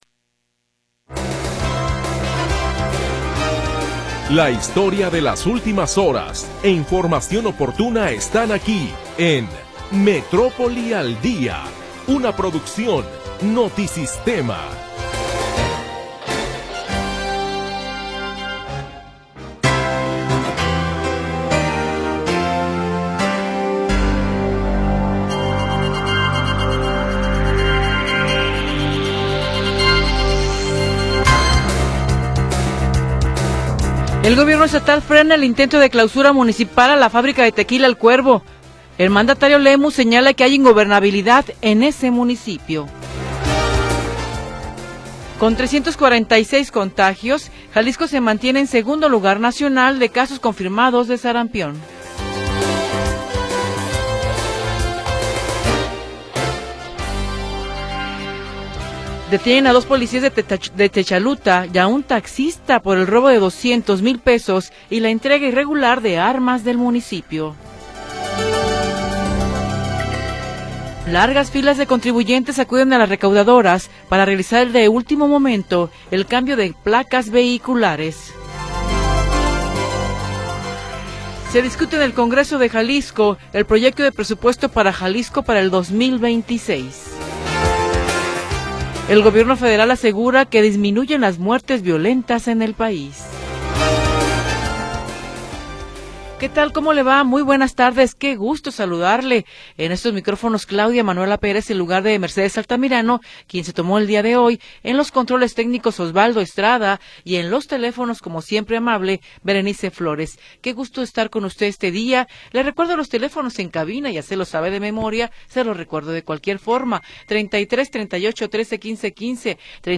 Primera hora del programa transmitido el 9 de Diciembre de 2025.